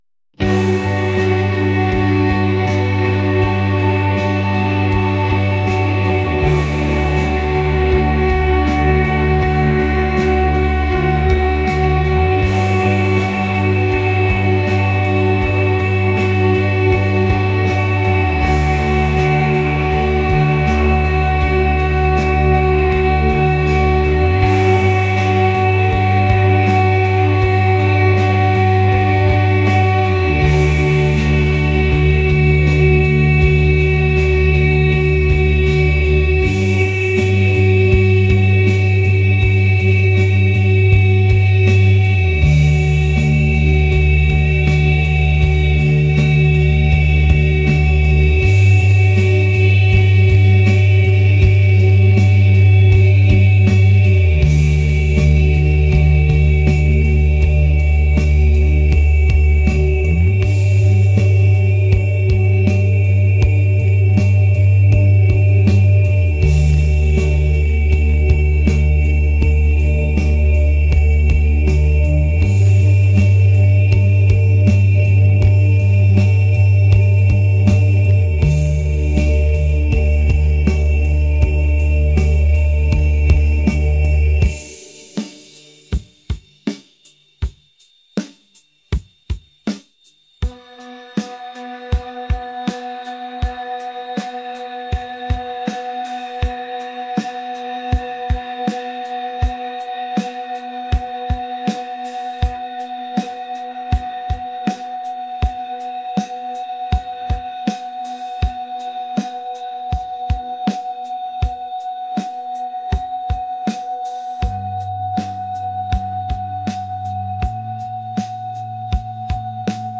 dreamy | ethereal